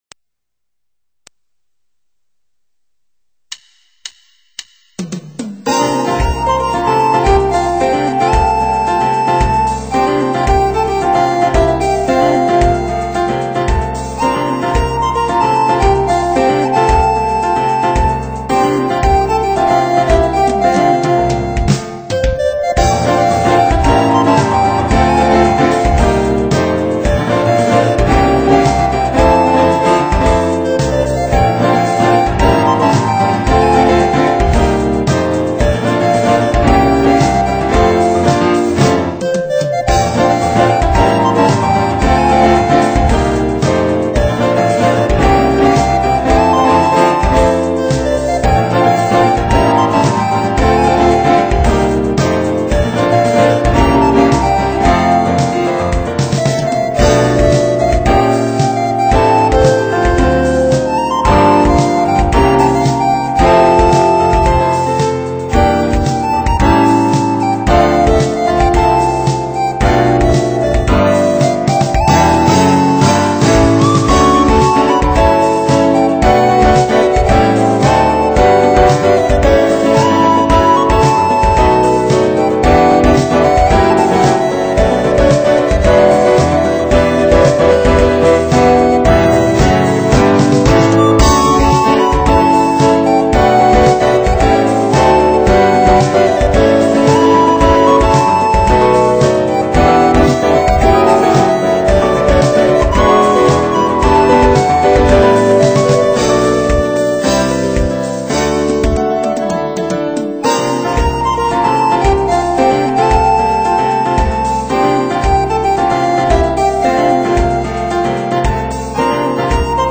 청아한게 꼭 오카리나 소릴 듣는듯한...